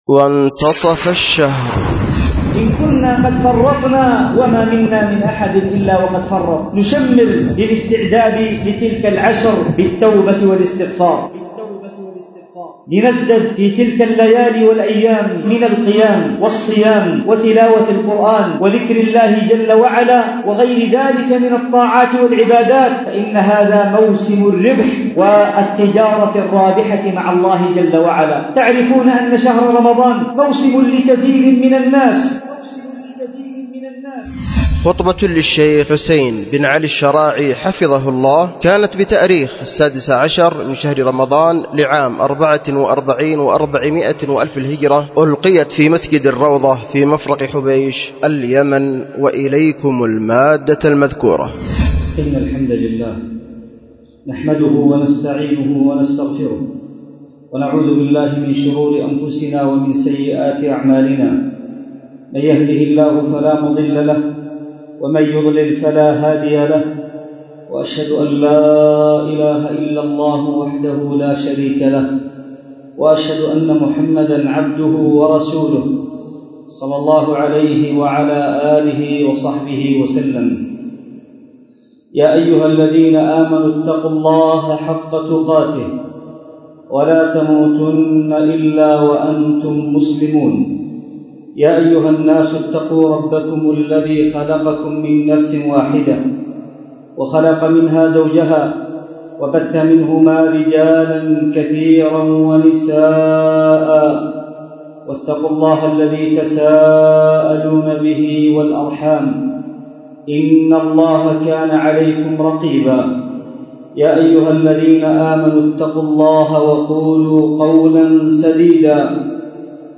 وانتصف الشهر 16رمضان 1444 – خطبة